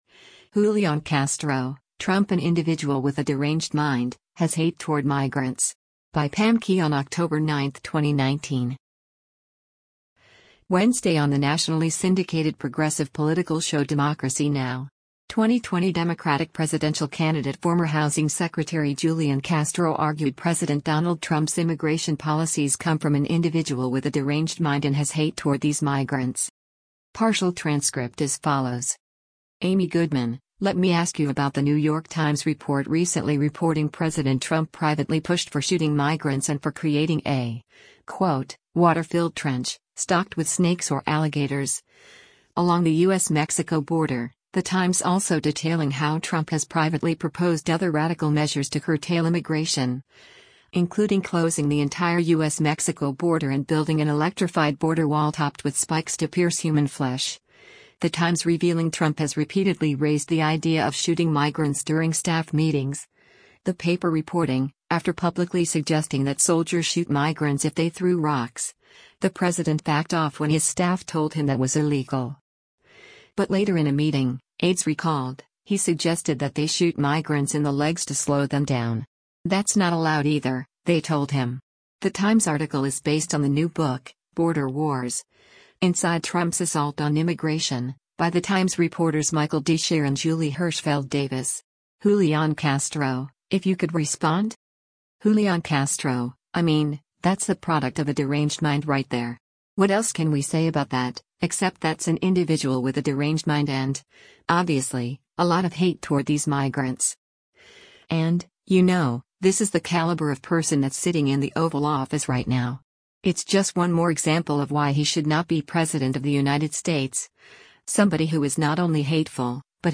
Wednesday on the nationally syndicated progressive political show “Democracy Now!” 2020 Democratic presidential candidate former Housing Secretary Julian Castro argued President Donald Trump’s immigration policies come from an “individual with a deranged mind” and has “hate toward these migrants.”